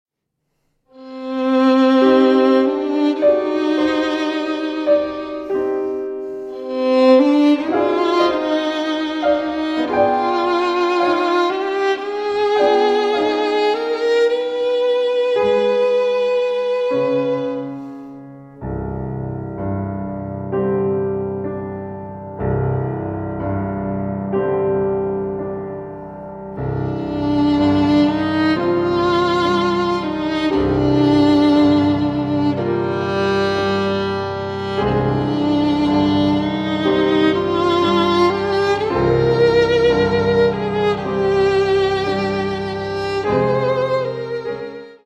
Lento (9:22)